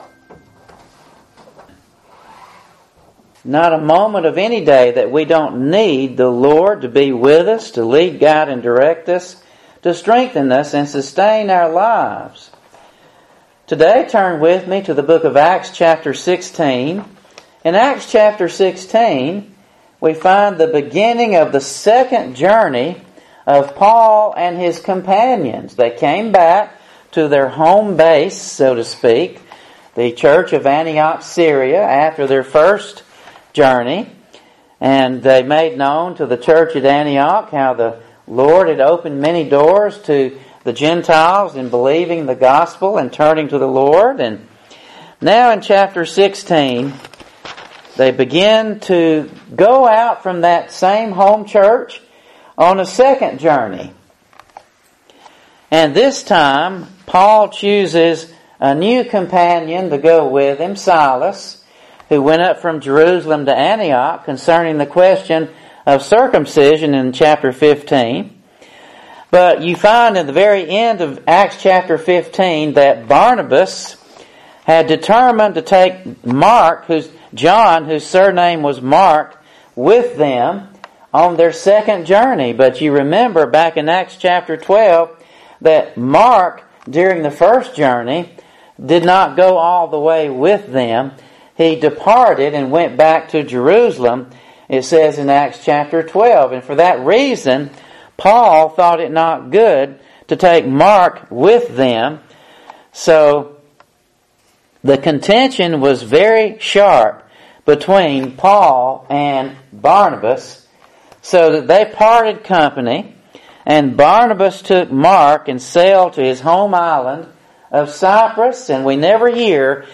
The Chastening of the Lord Aug 5 In: Sermon by Speaker